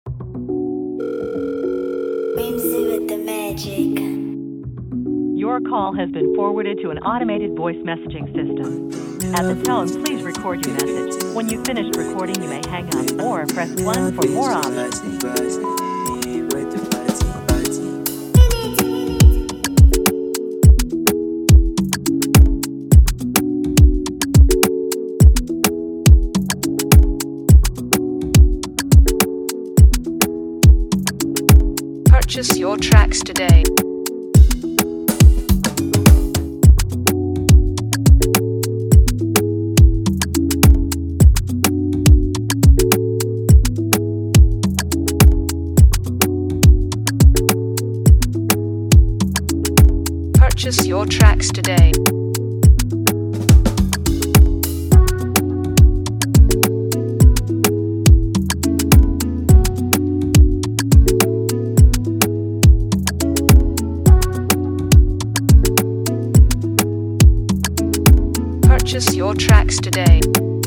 a scintillating Afrobeat instrumental